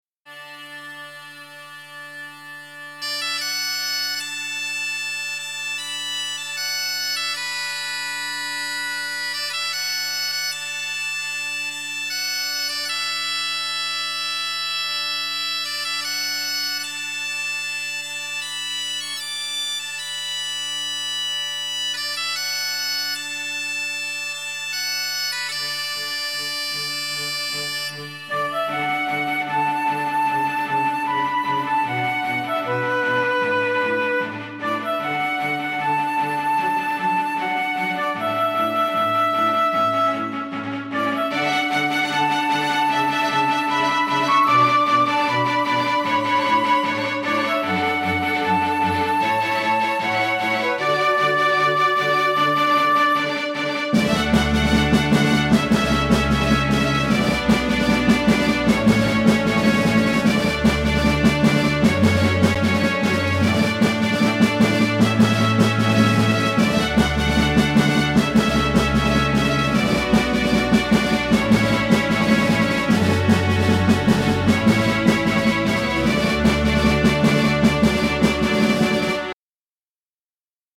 Note that I haven't really bothered making sure that the bounces are great, so there are a few clicks here and there.
A lilting Gaelic track, with, I think, quite a pretty melody.